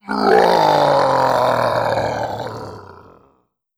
Monster Roars
03. Defiant Roar.wav